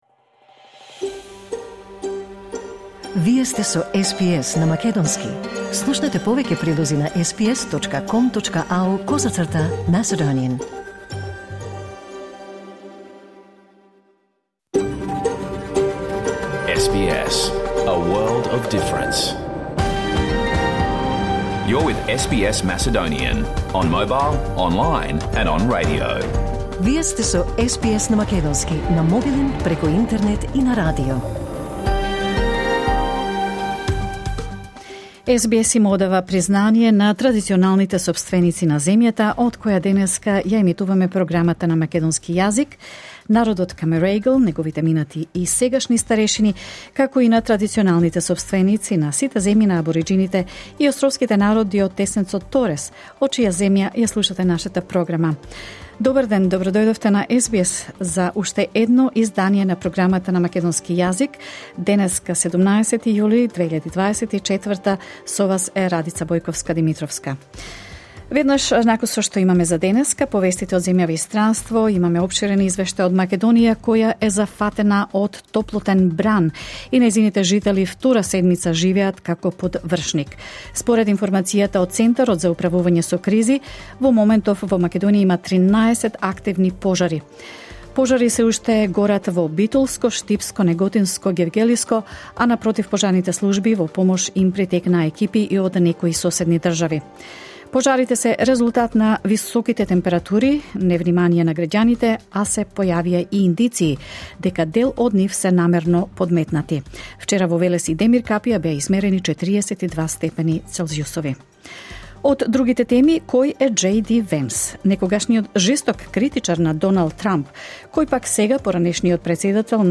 SBS Macedonian Program Live on Air 17 July 2024